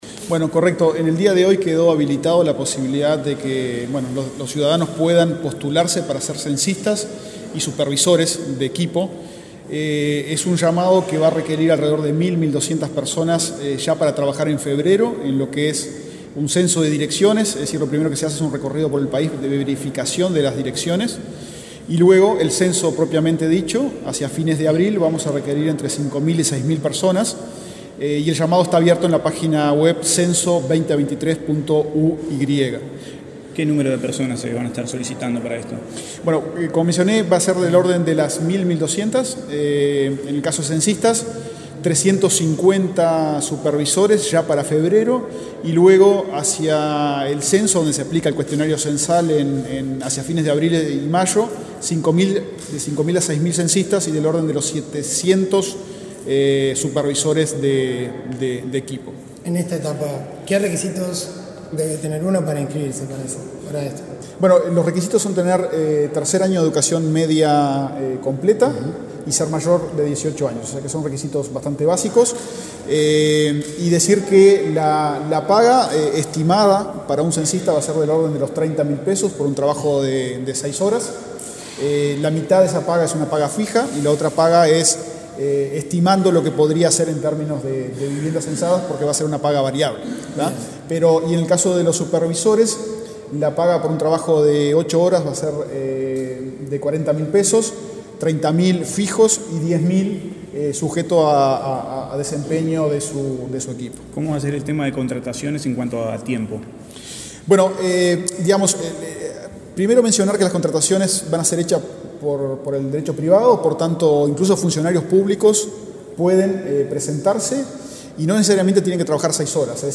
Declaraciones del presidente del INE, Diego Aboal
Luego dialogó con la prensa.